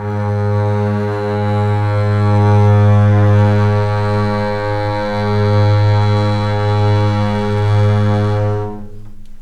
G#2 LEG MF R.wav